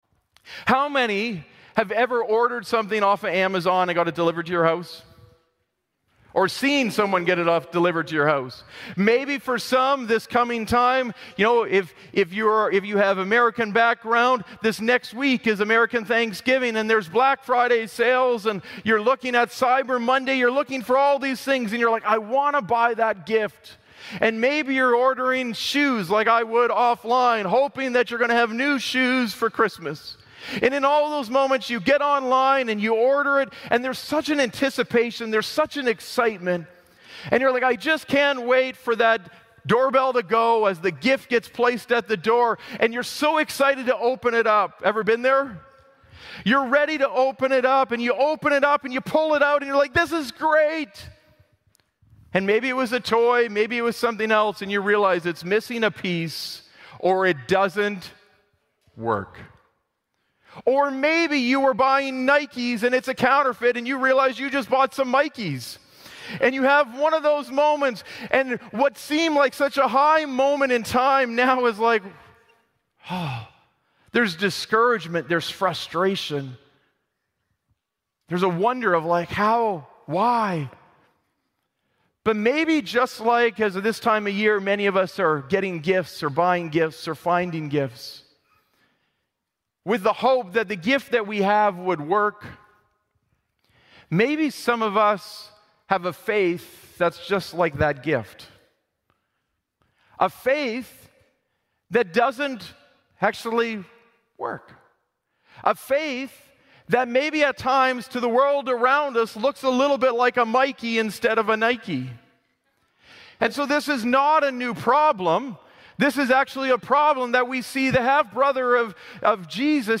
Glad Tidings Church (Sudbury) - Sermon Podcast Keep Faith Alive Play Episode Pause Episode Mute/Unmute Episode Rewind 10 Seconds 1x Fast Forward 30 seconds 00:00 / 34:46 Subscribe Share RSS Feed Share Link Embed